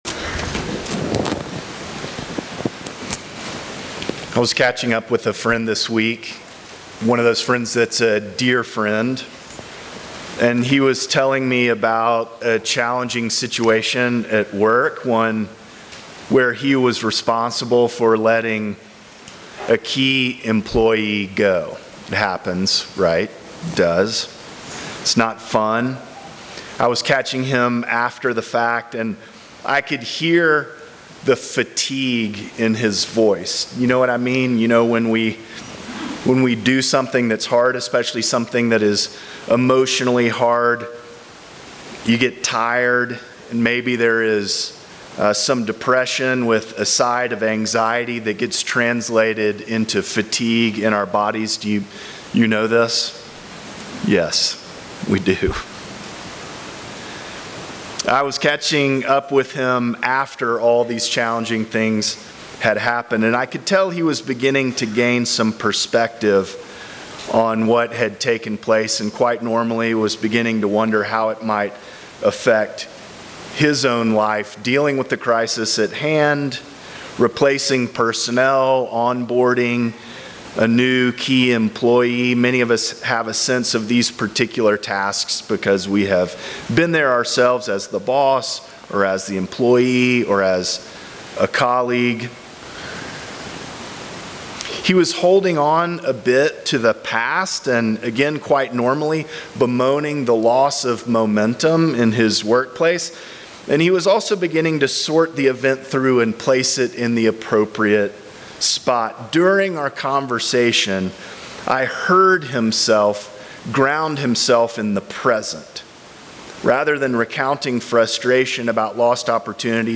Sermons
St. John's Episcopal Church